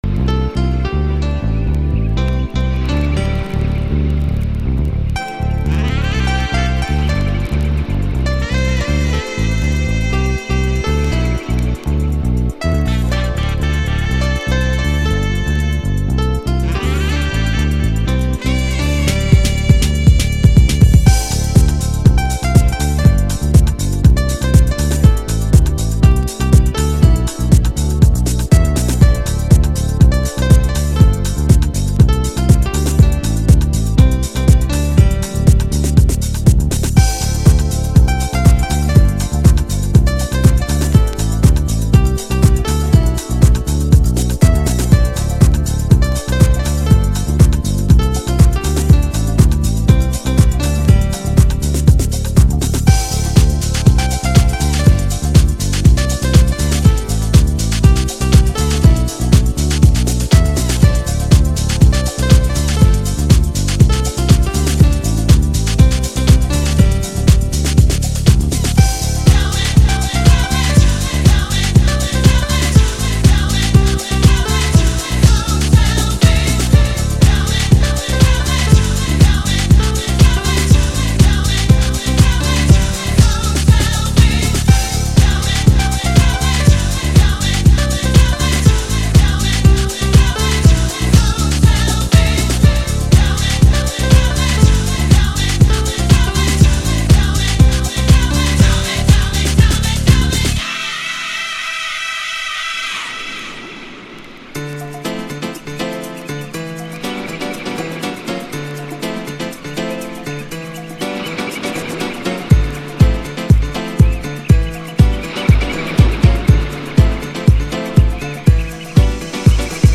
Early House / 90's Techno
US HOUSEの影響化メロディアスな部分を詰め込んで、しっかり粘着ベースでグルーヴするイタロハウスです。